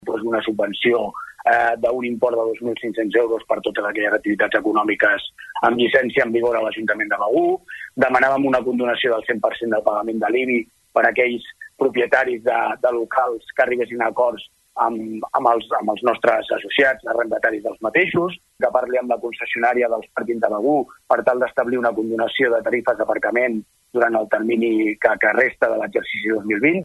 BegurEntrevistes Supermatí